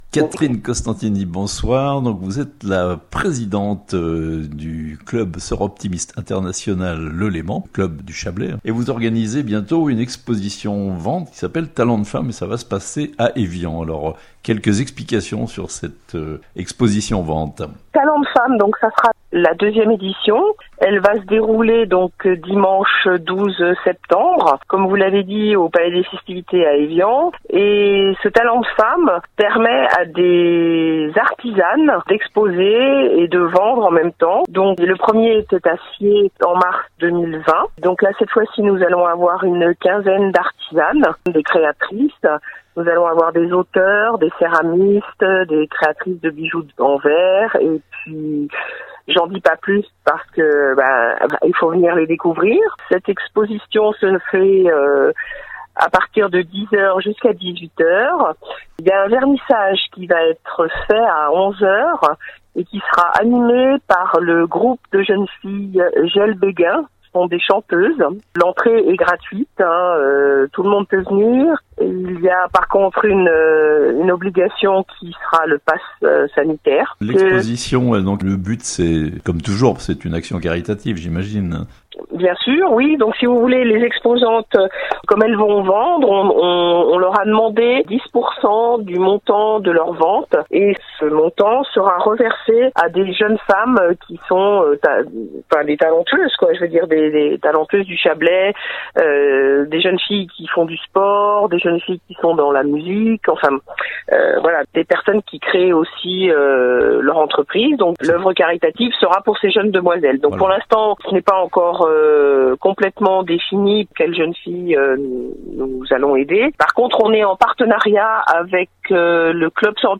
Une exposition d'artistes et créatrices à Evian (interview)